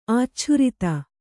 ♪ ācchurita